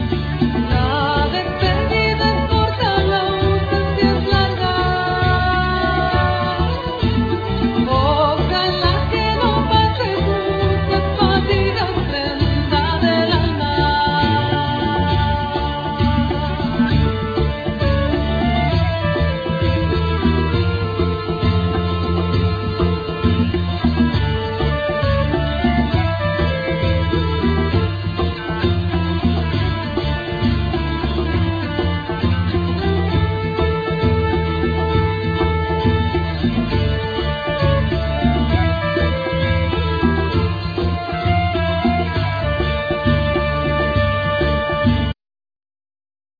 Vocals,Chorus,Keyboards
Gaita,Gallega,Gaida bulgara,Flute,
Bouzuki,Saz,Tamboril
Tabla,Zarb,D7rbouka,Pandero,Djambe,Effects
Zanfona,Riq
Accordion
Clarinet,Bass clarinet
Saxophone,Flute